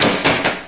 sparkShoot1.ogg